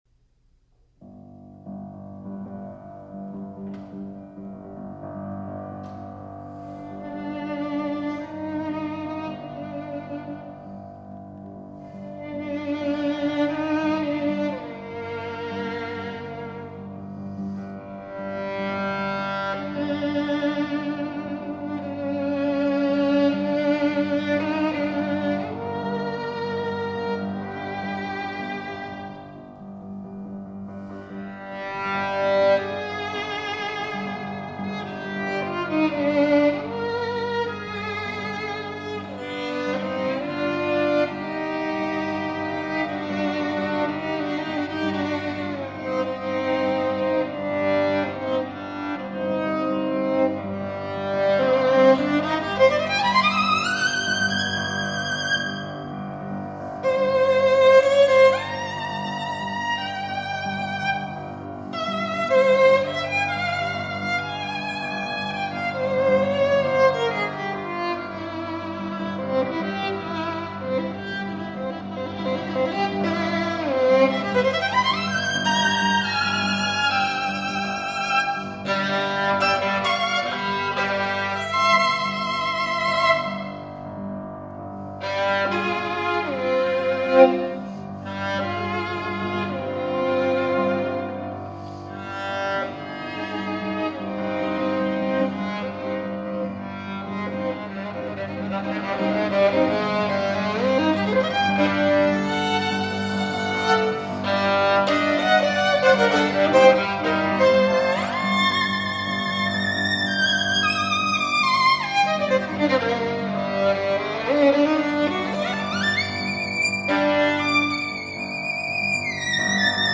Voicing: Violin pn